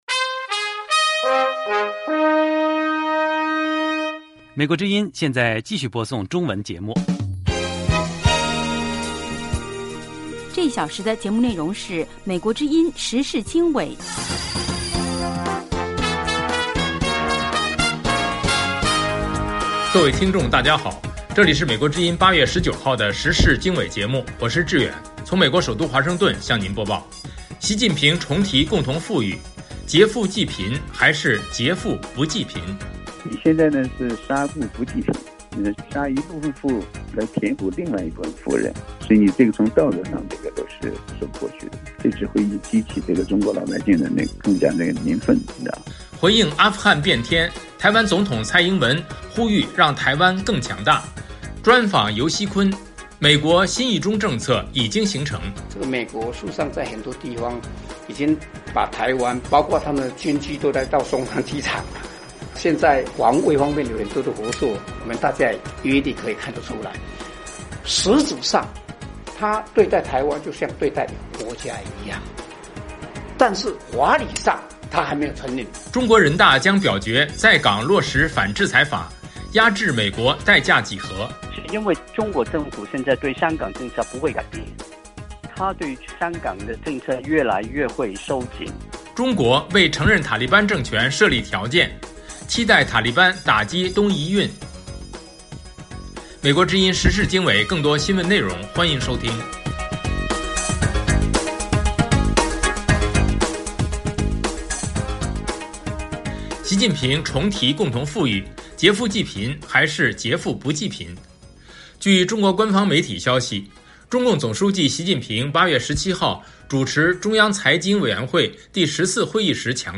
时事经纬(2021年8月19日)：1/习近平重提共同富裕，“劫富济贫”还是“劫富不济贫”？2/回应阿富汗变天，台湾总统蔡英文呼吁让台湾更强大。3/专访游锡堃：美国“新一中政策”已经形成。